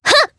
Sonia-Vox_Attack1_jp.wav